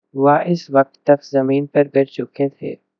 deepfake_detection_dataset_urdu / Spoofed_Tacotron /Speaker_03 /15.wav